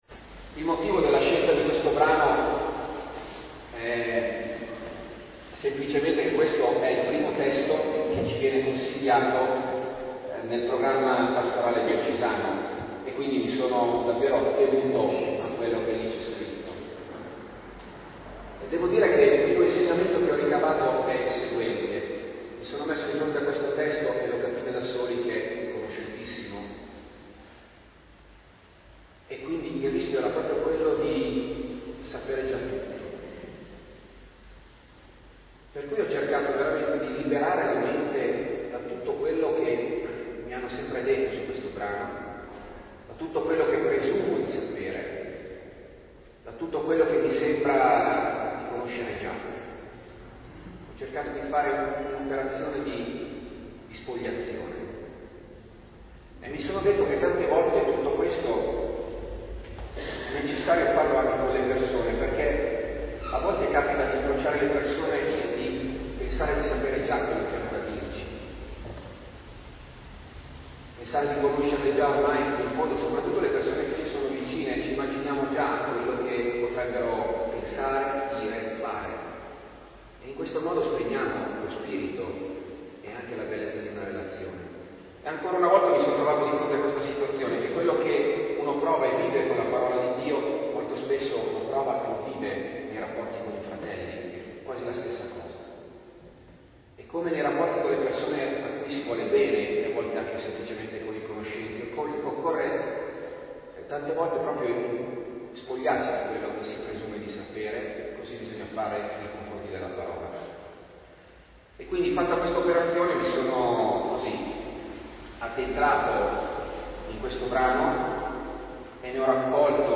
Lectio inizio anno Pastorale